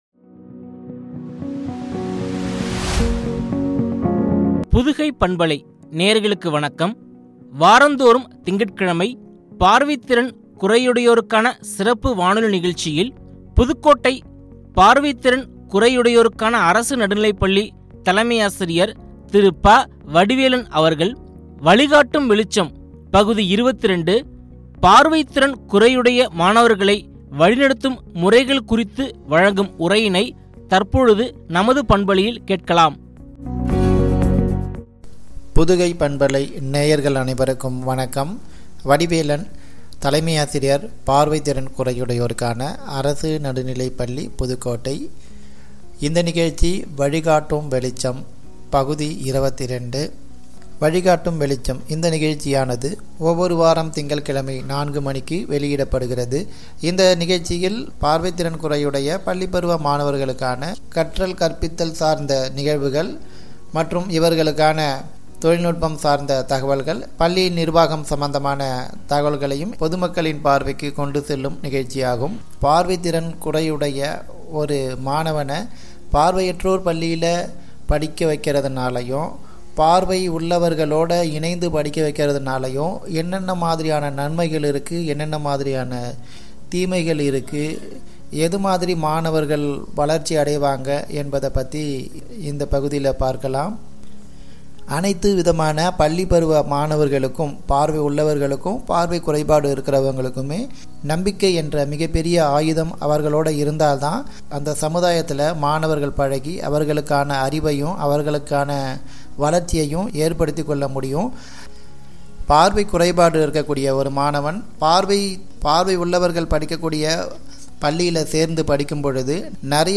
பார்வை திறன் குறையுடையோருக்கான சிறப்பு வானொலி நிகழ்ச்சி